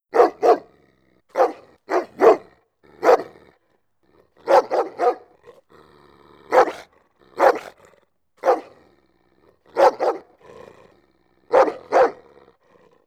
Dog_Large_barking-01.wav